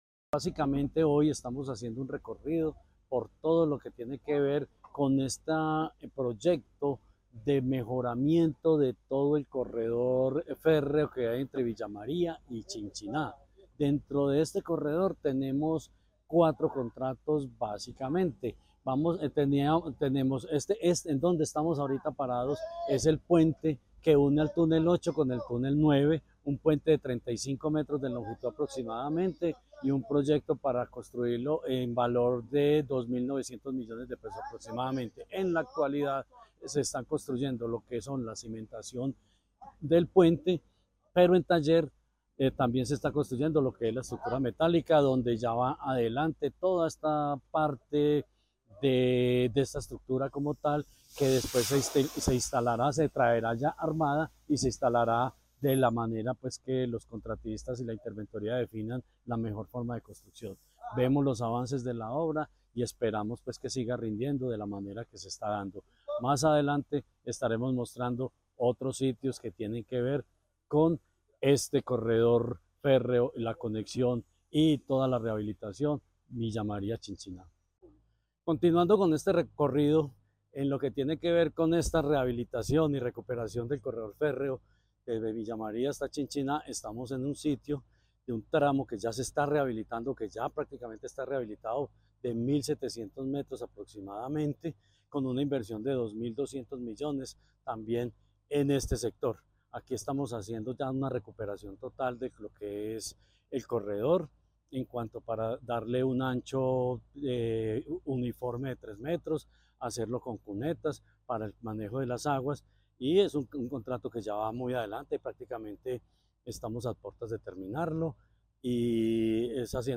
Secretario de Infraestructura de Caldas, Jorge Ricardo Gutiérrez Cardona.
Jorge-Ricardo-Gutierrez-Cardona-Corredor-verde.mp3